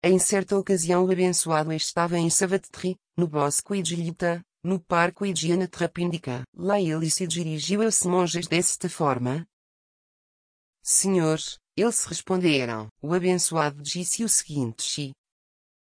Inês is one of two Amazon Web Services (AWS) Polly Portuguese voices.
As you can hear from the example above, the Portuguese voice has omitted “Bhikkhus”, so we shall have to work out the Portuguese pronunciation of any Pali word included in translations.
Hi there, the accent is Portuguese Portuguese, which will sound ok for Portuguese people (10 million) odd to the majority of Portuguese speakers in Brazil (205 million people).
Also, it is a bit too fast, maybe could we slow it down by a 0.1 or 0.2x factor?